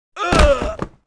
SFX男呻吟倒地6音效下载
SFX音效